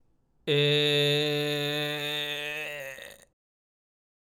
最後に、G(え)+仮声帯(普通からじりじり入れる)